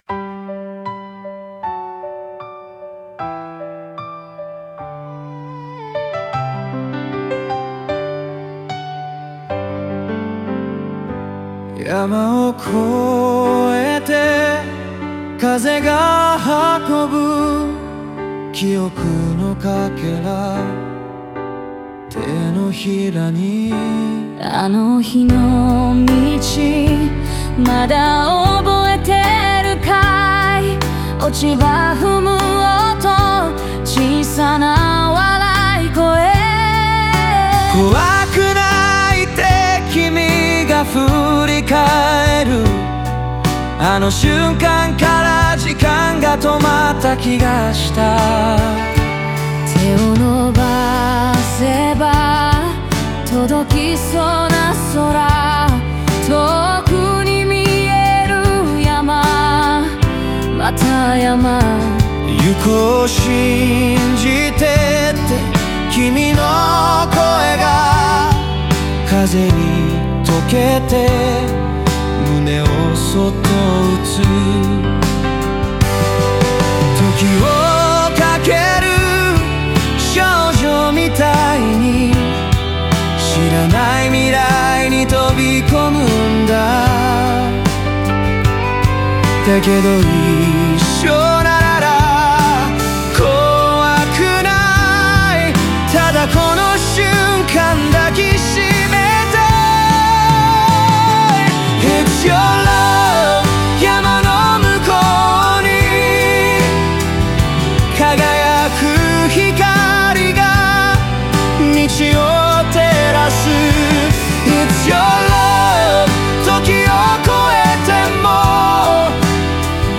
オリジナル曲♪
男女デュエット形式を用いることで、互いの声が重なり合う瞬間に生まれる温もりや信頼を強調。